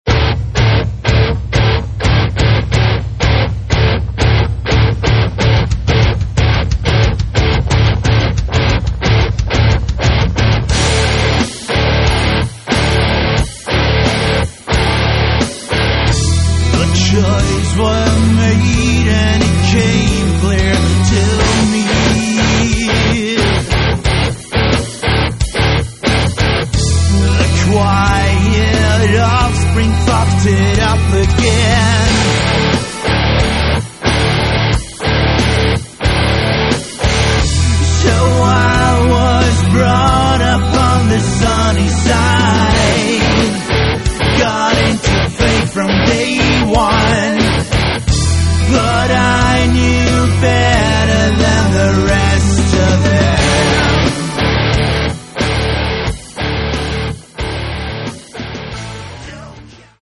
Рок
Более рок-н-ролльный дух, настроение шоу.